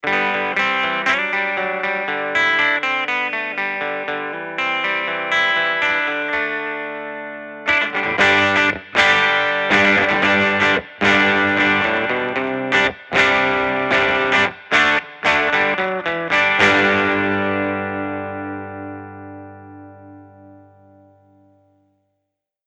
Próbki dzwiękowe Audio Technica AE-3000
Audio Technica AE3000 mikrofon - gitara elektryczna